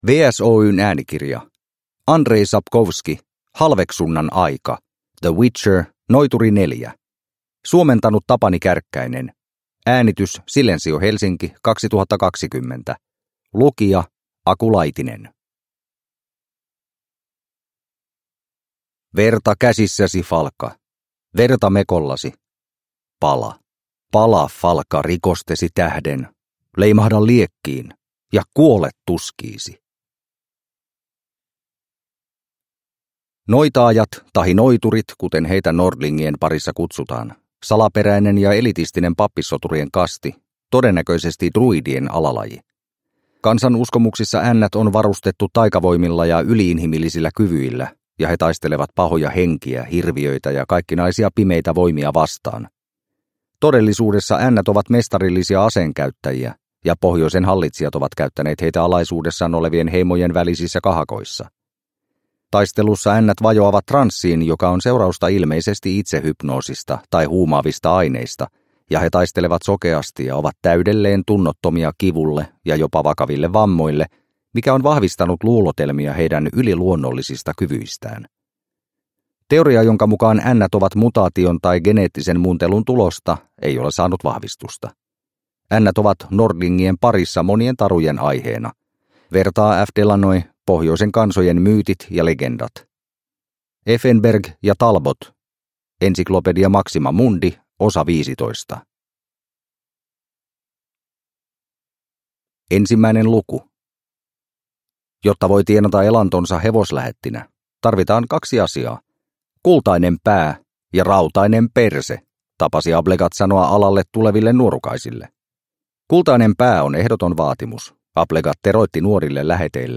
Halveksunnan aika (ljudbok) av Andrzej Sapkowski